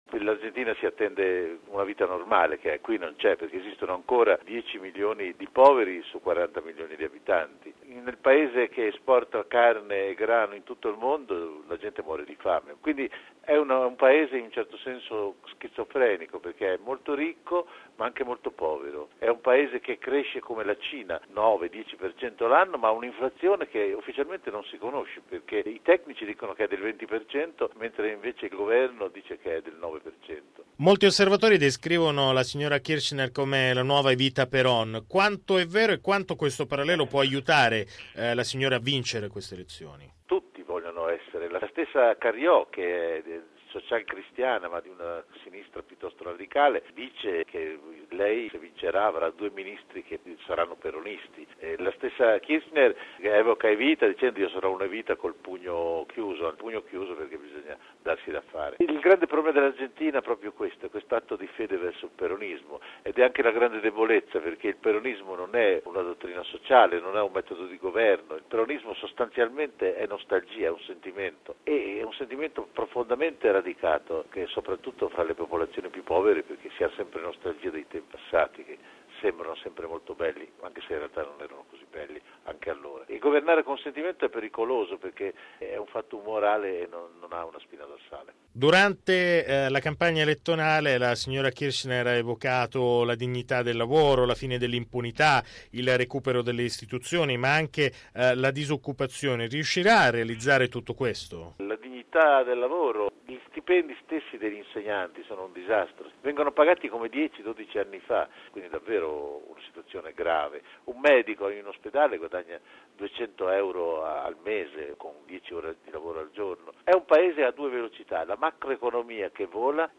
raggiunto telefonicamente a Buenos Aires: